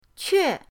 que4.mp3